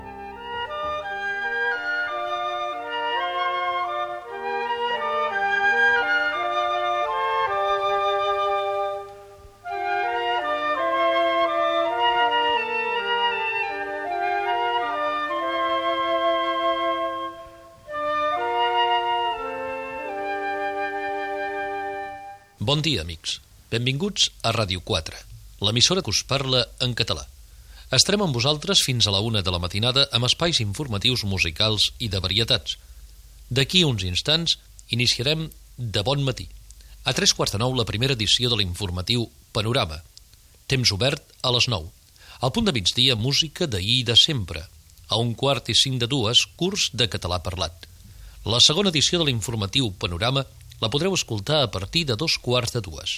Segon fragment: recreació semblant, afegint Radio Nacional de España.